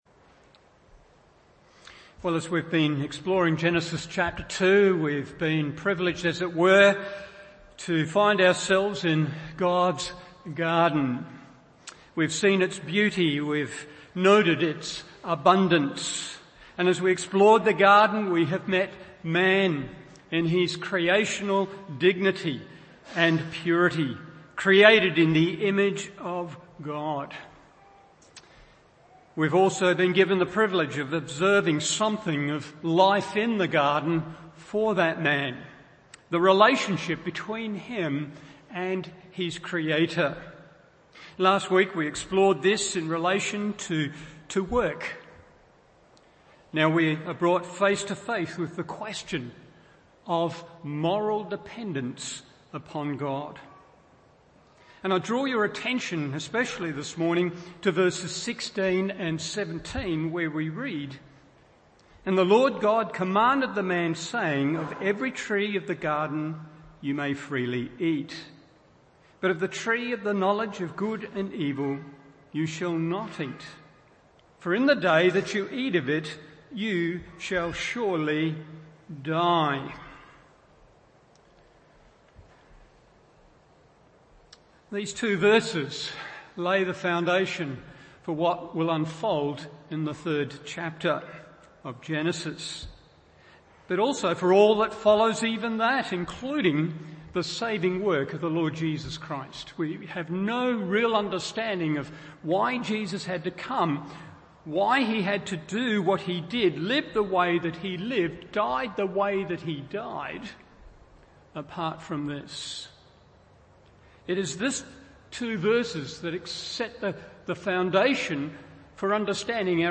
Morning Service Genesis 2:16-17 1.